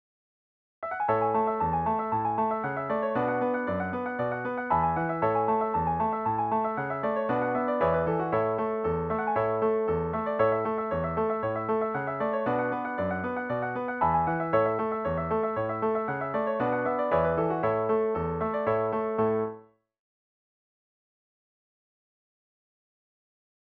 DIGITAL SHEET MUSIC - PIANO ACCORDION SOLO
Traditional Tunes, New England Reel